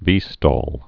(vēstôl)